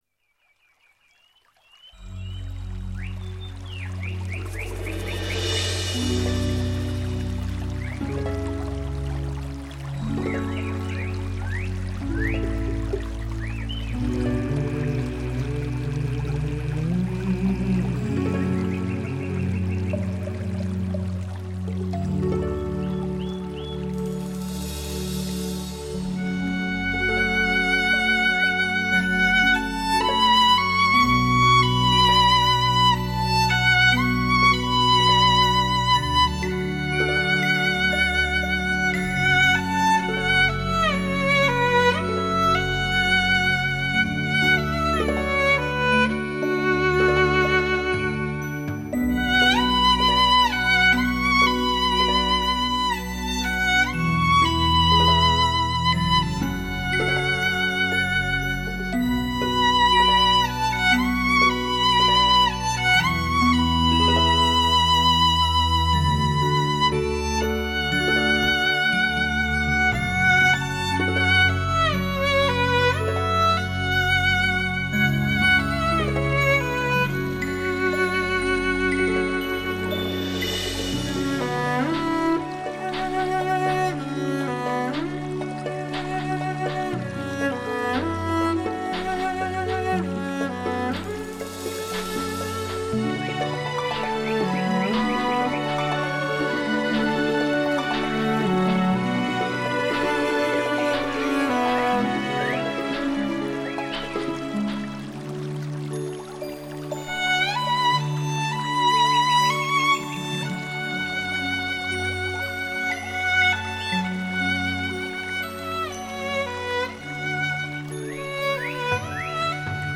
宁静·空灵·飘逸 美妙天籁般的天堂乐声
仿佛来自仙界 醉听极美空灵的天籁之音
小提琴独奏